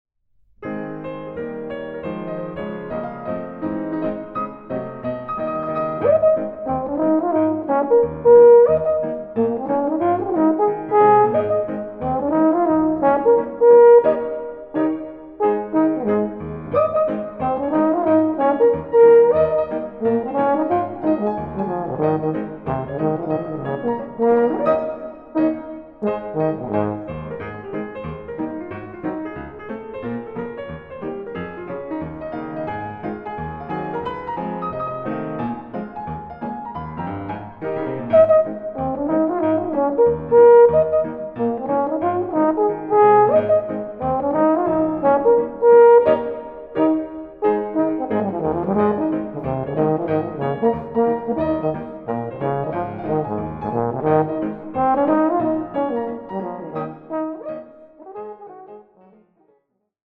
Aufnahme: Mendelssohn-Saal, Gewandhaus Leipzig, 2025
Version for Euphonium and Piano